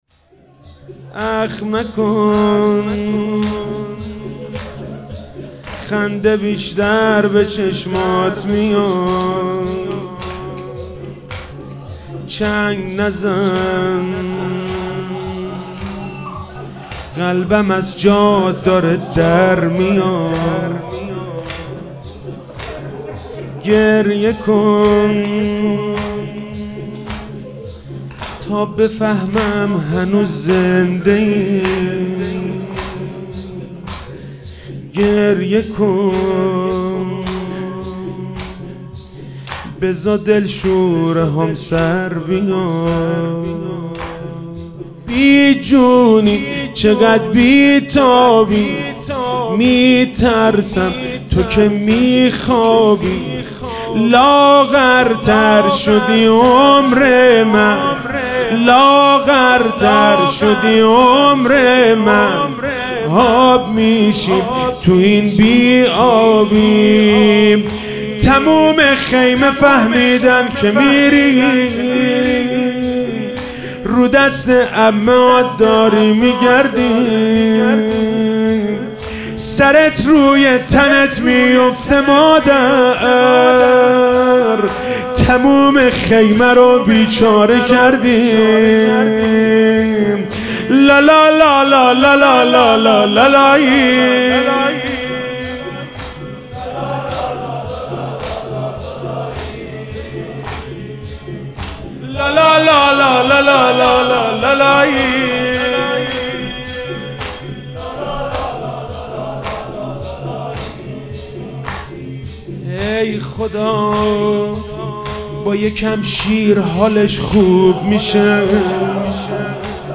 محرم99 - شب هفتم - زمینه - اخم نکن خنده بیشتر به چشمات میاد